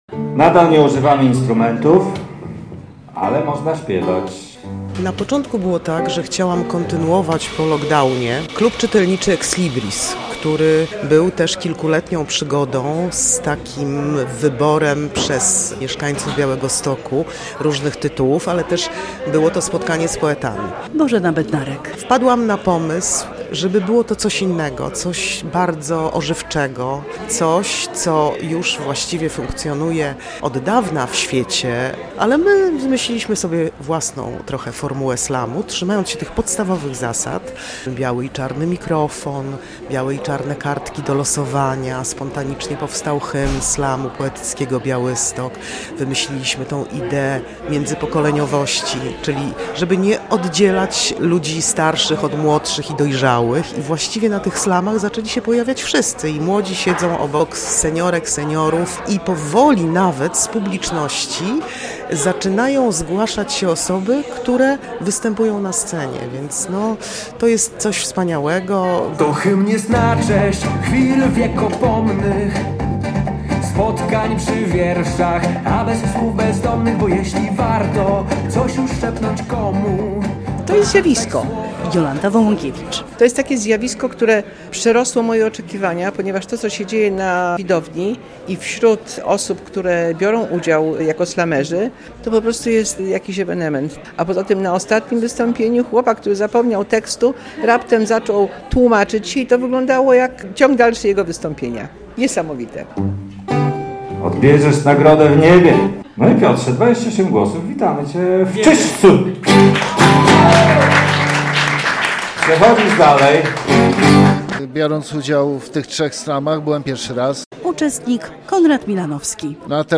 relacja
I przy okazji projekcji odbył się kolejny mini slam.